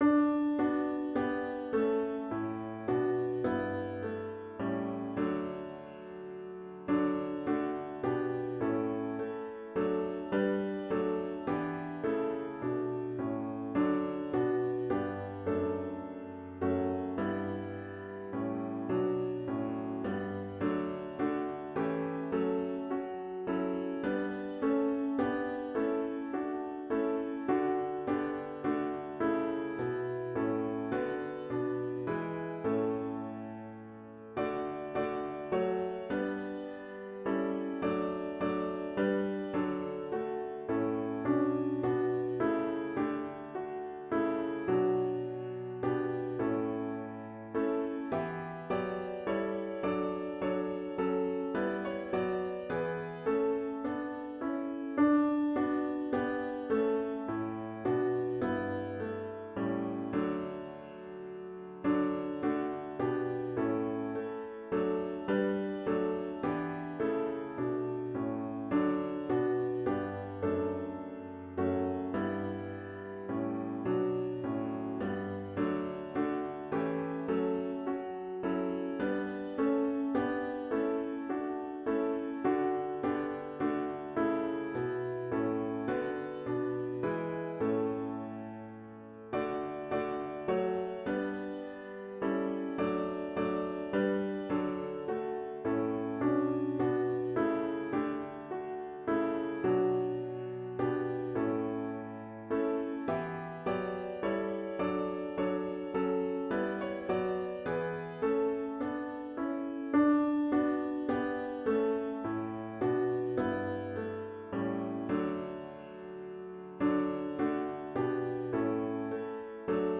*HYMN “I Want to Walk as a Child of the Light” GtG 377